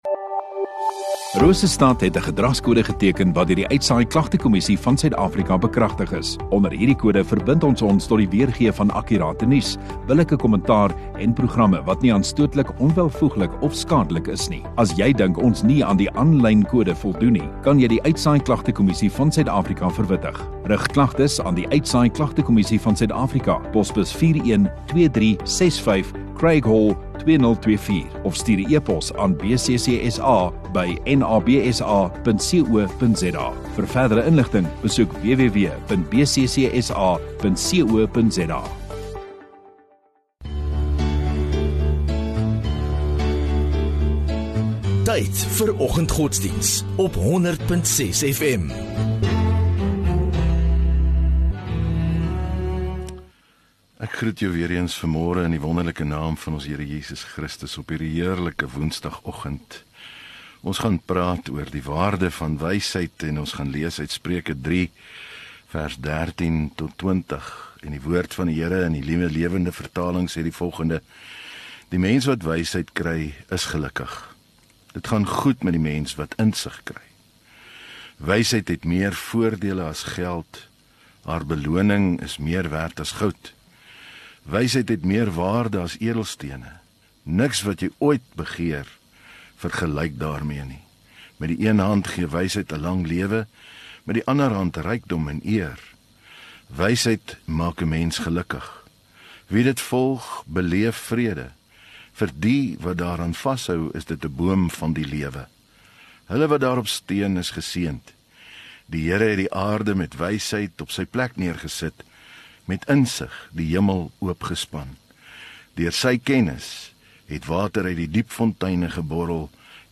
9 Apr Woensdag Oggenddiens